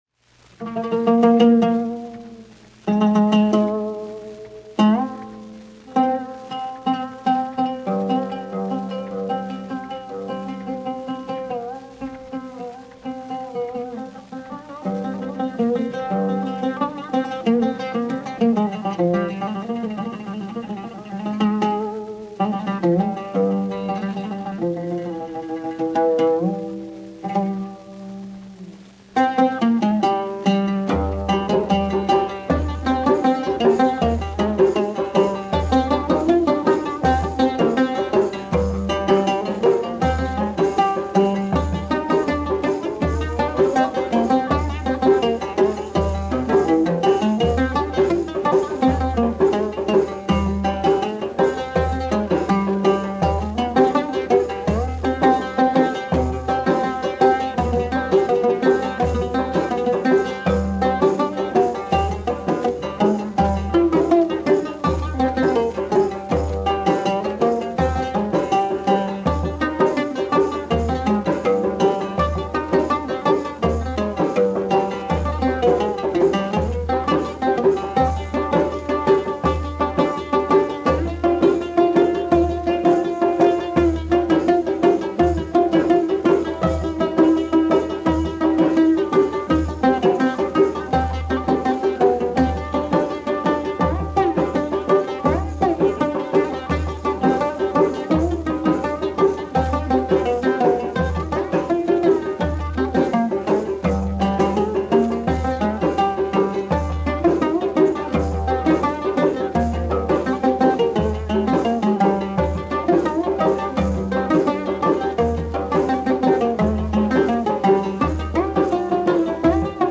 the oud solo on this track